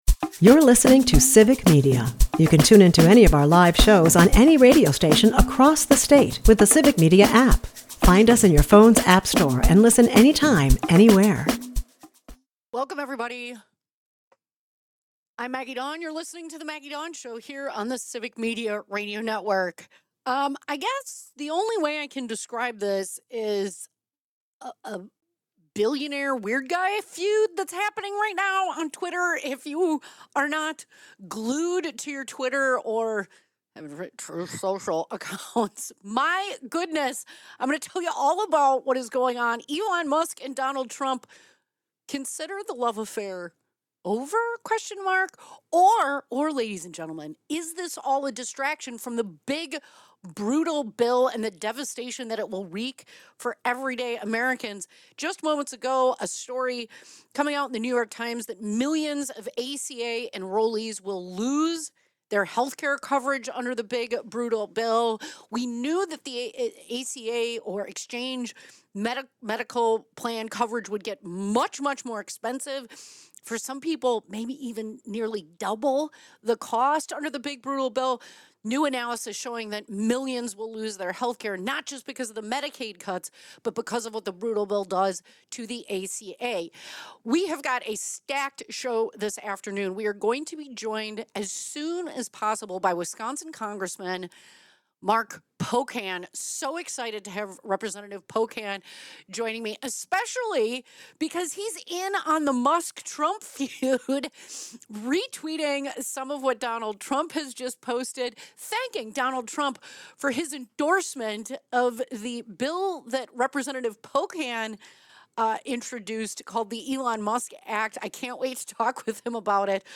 Wisconsin Congressman Mark Pocan joins to weigh in—not just on the feud, but on the devastating consequences of the so-called “Big Brutal Bill,” which threatens healthcare for millions of ACA enrollees. Pocan exposes hidden Medicare cuts buried in the bill and calls out the MAGA regime’s deceptive policies, including dangerous falsehoods spread by figures like Russell Vought.